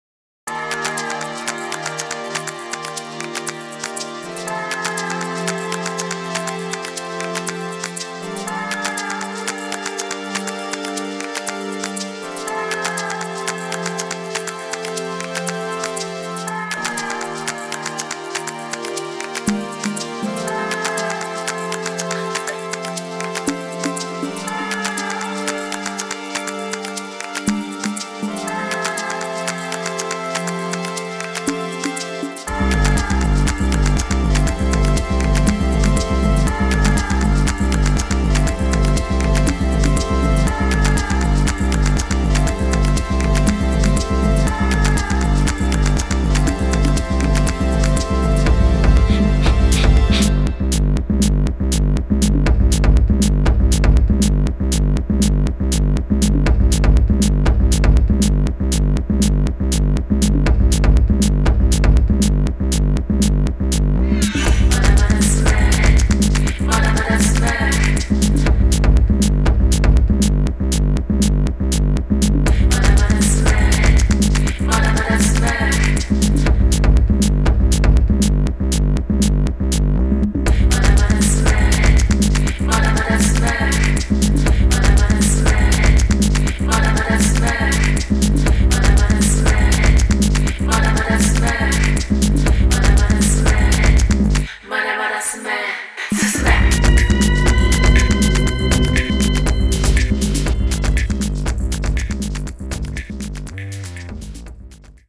太いベースラインと、トライバル感